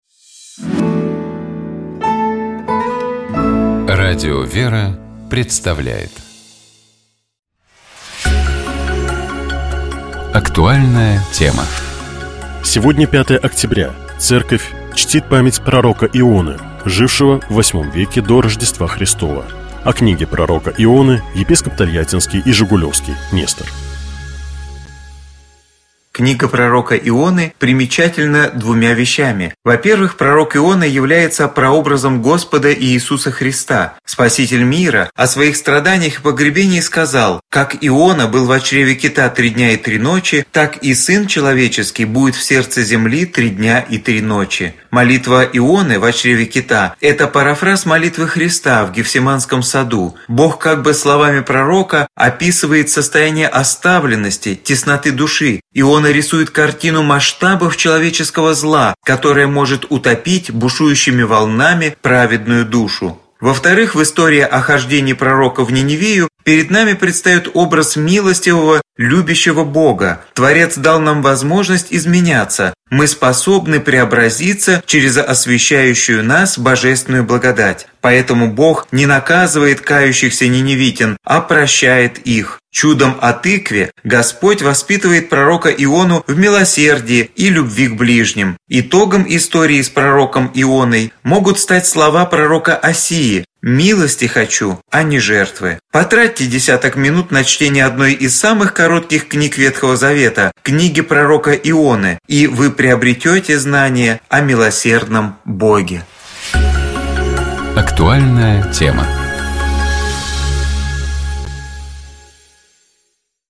О Книге пророка Ионы, — епископ Тольяттинский и Жигулёвский Нестор.